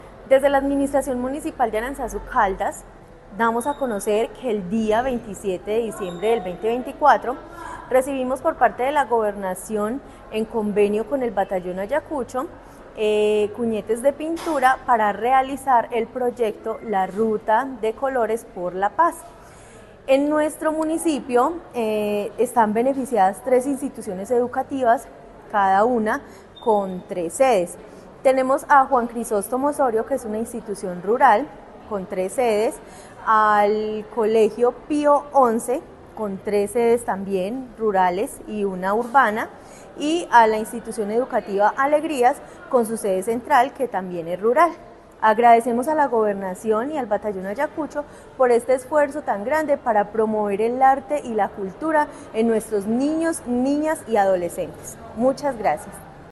Luz Marina Alzate García, secretaria de Desarrollo Social y Comunitario de Aranzazu.
Luz-Marina-Alzate-Garcia-secretaria-Desarrollo-Social-Aranzazu-1.mp3